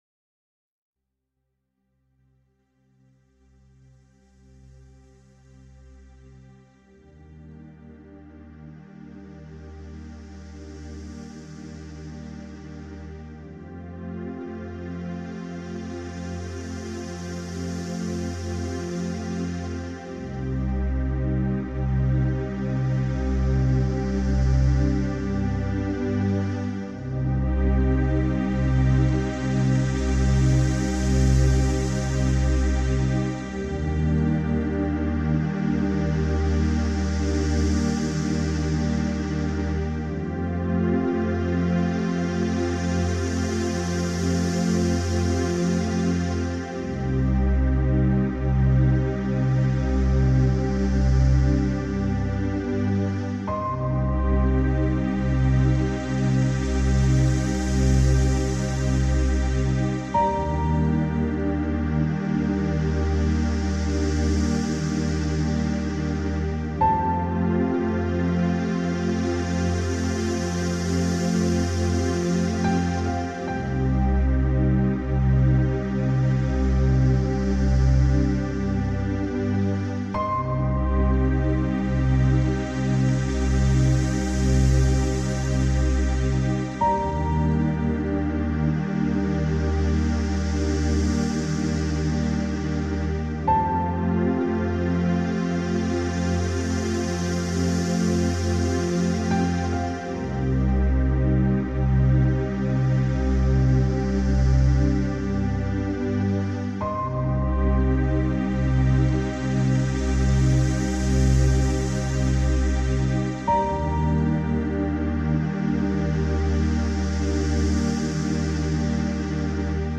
RelaxationMusic-1HourMeditationCandle.mp3